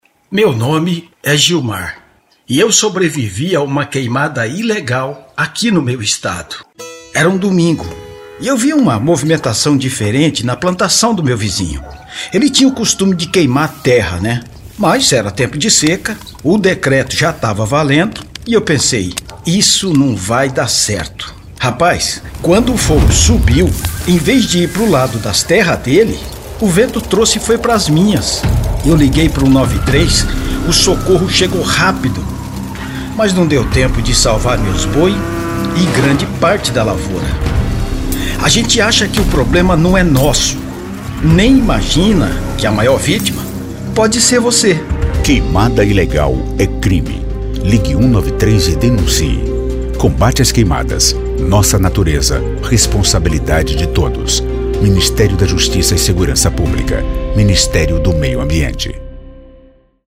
secom_spot_60-queimadas-carro-de-som.mp3